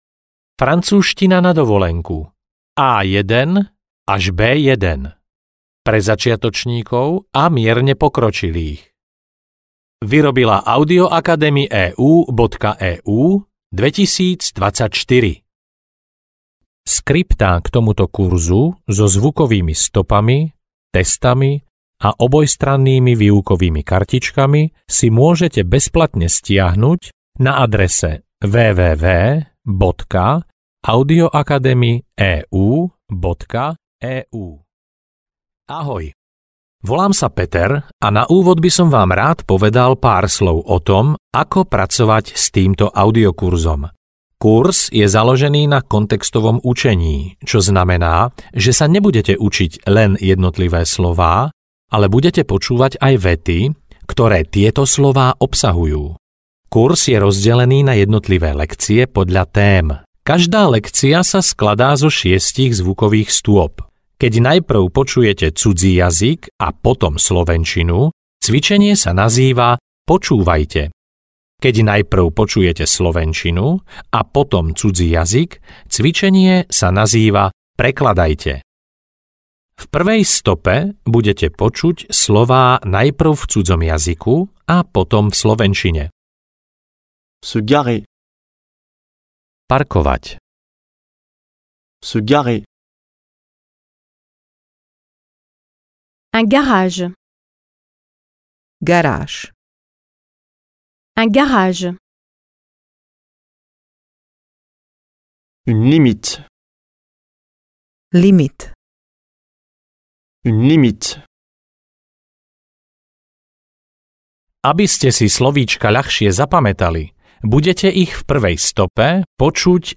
Francúzština na dovolenku A1-A2 audiokniha
Ukázka z knihy